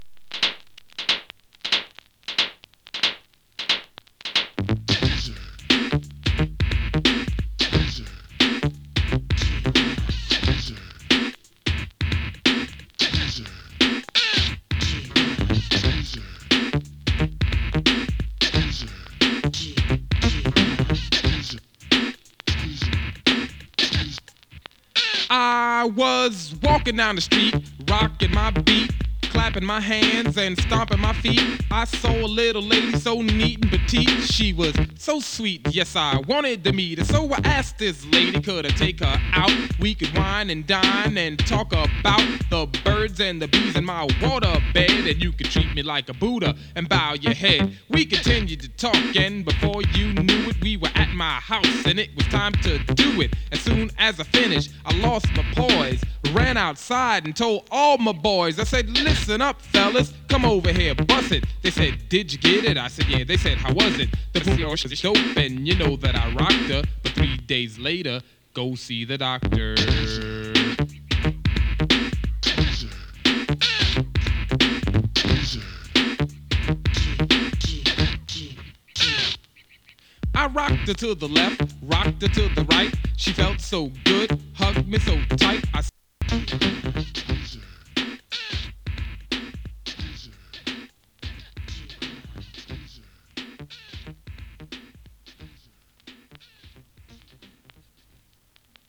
エレクトロ オールドスクール
HIPHOP ジャケ付き7inchシングル！
[2version 7inch]＊音の薄い部分で軽いチリパチ・ノイズ。